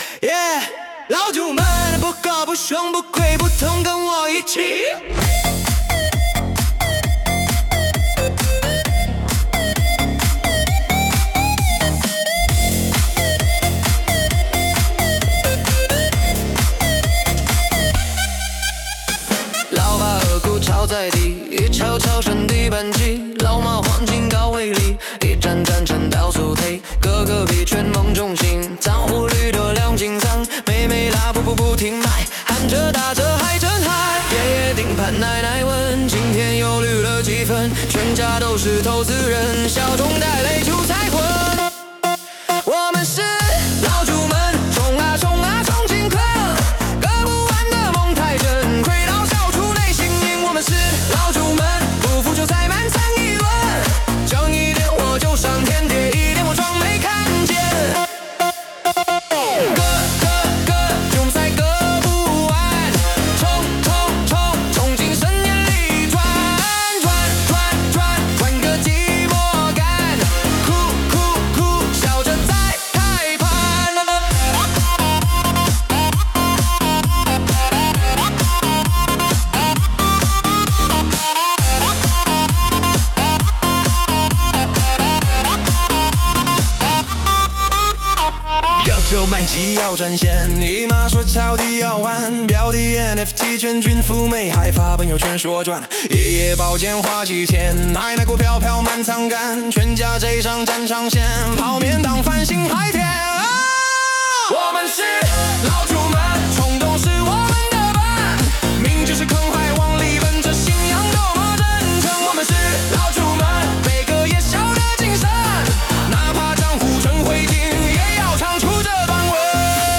2025-11-20 分类：Ai精选歌曲 阅读(135) 评论(0)